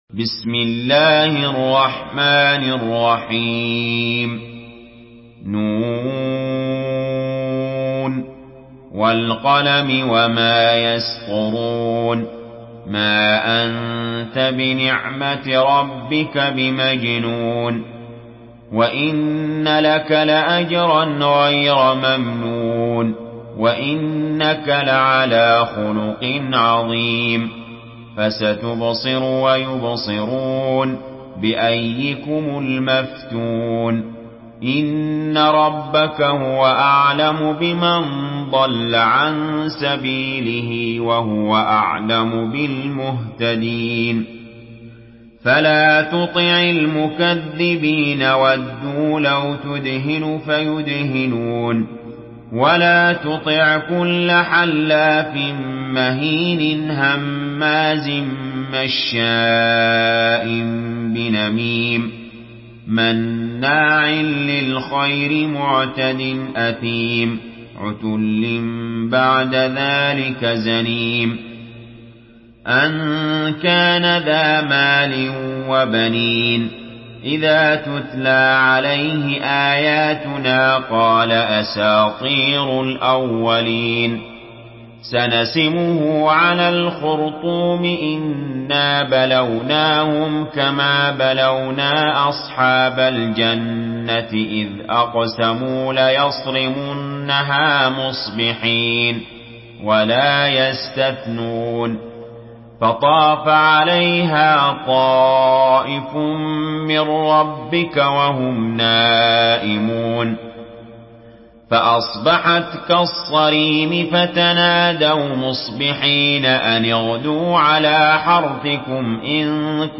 Surah Kalem MP3 by Ali Jaber in Hafs An Asim narration.
Murattal Hafs An Asim